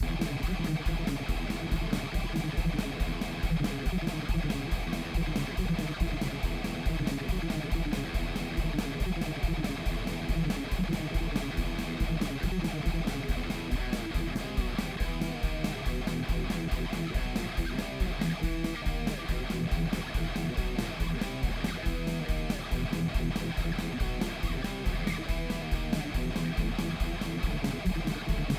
140 bpm riffs [disquiet0591]
A couple of riffs, plus some improv at the end.
Metal